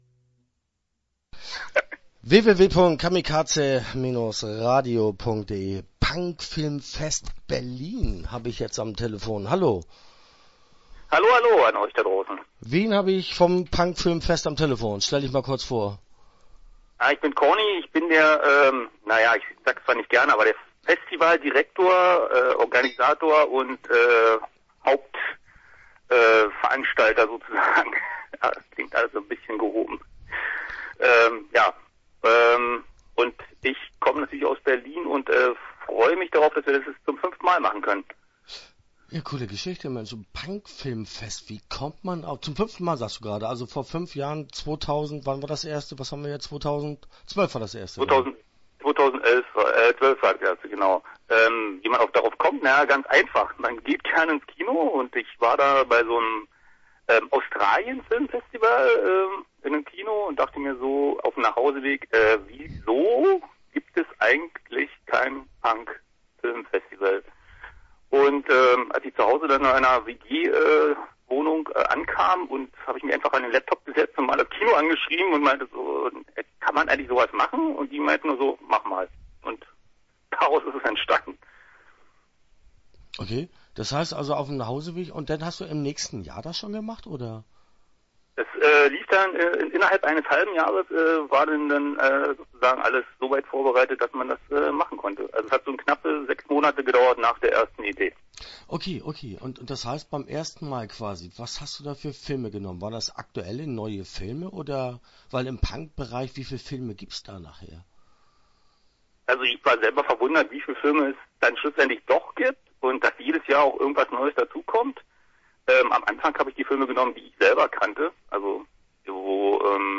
Start » Interviews » PunkFilmFest